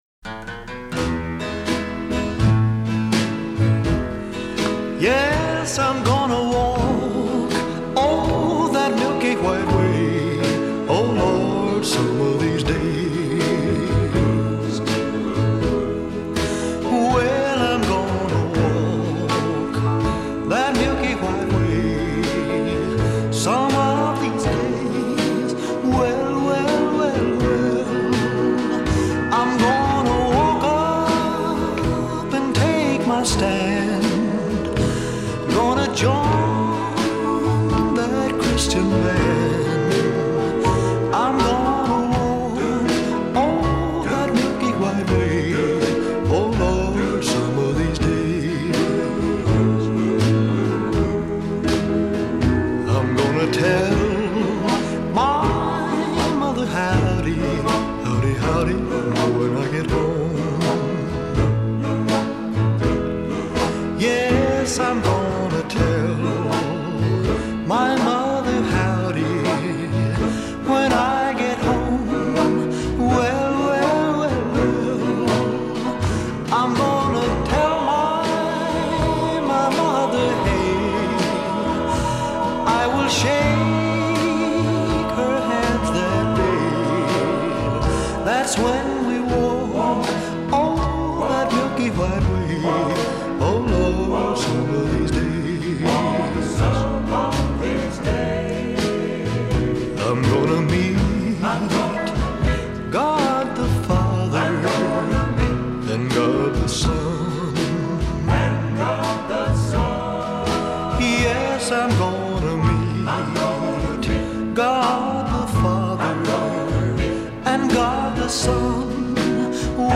full-on gospel rockin’